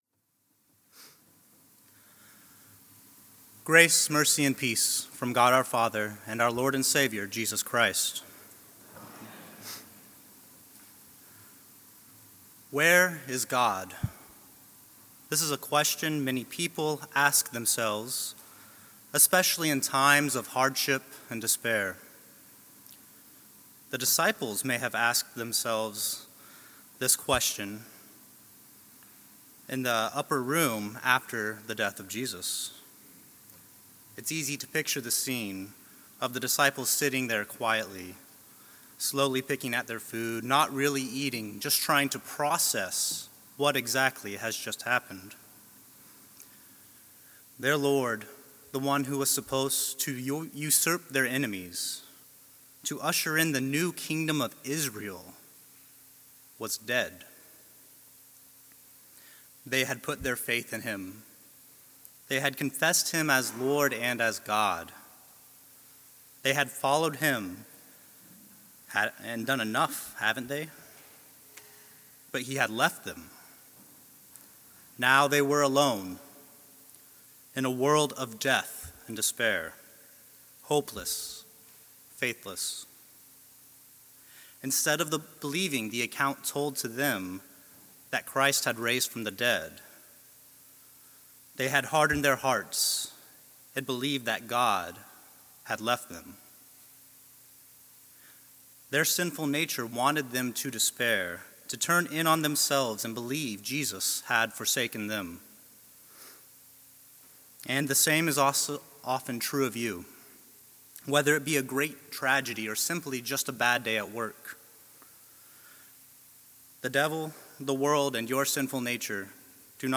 Sermons
The Ascension of Our Lord